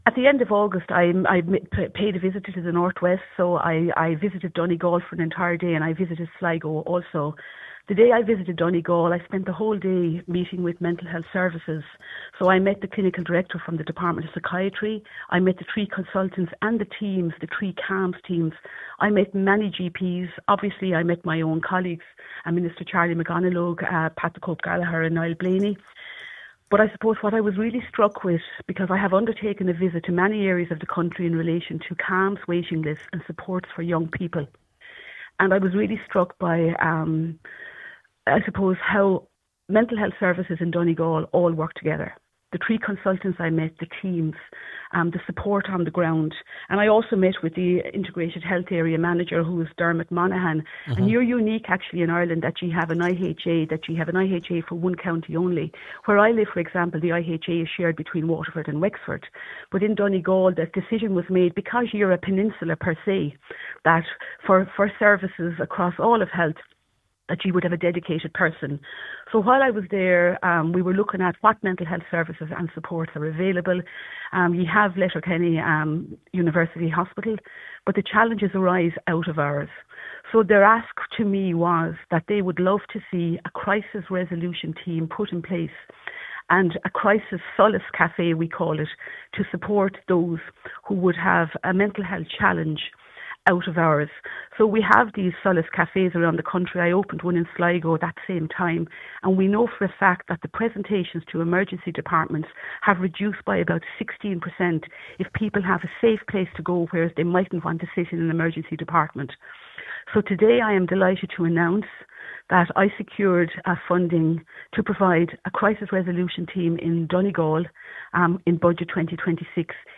On today’s Nine til Noon Show, Government Chief Whip and Mental Health Minister Mary Butler confirmed that the budget allows for the appointment of a Crisis Resolution Team for Donegal, and the provision of a Solace Café.